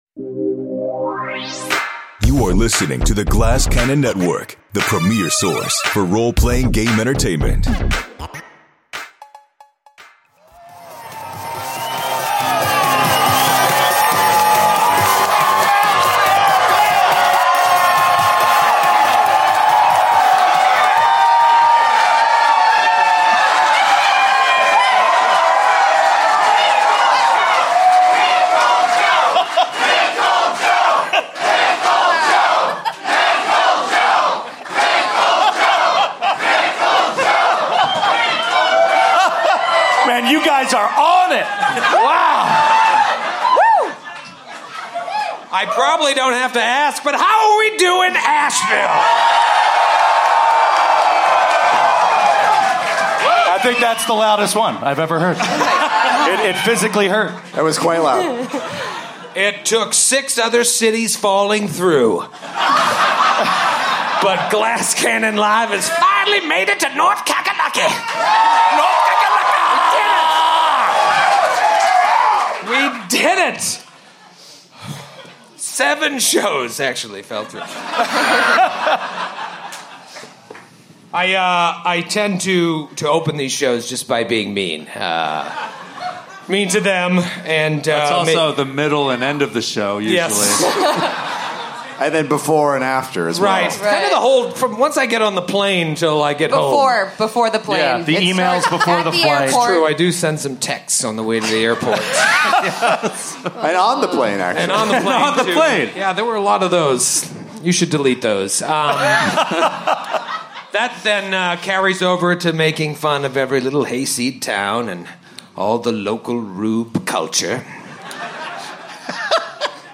Glass Cannon Live! makes its first stop to Asheville, NC, for a meat wall filled night of innuendo and mortal combat!